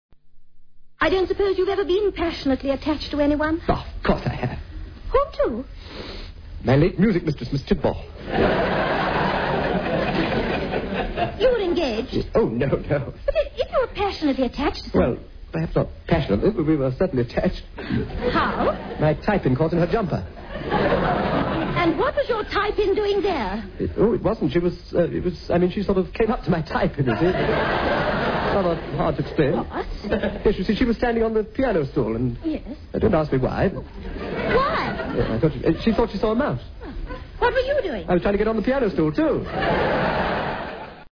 'The Army, the Navy and the Air Force' whistled by a forces audience opened this show which 'week by week goes round the services bringing music and fun to boys and girls in khaki and two shades of blue'.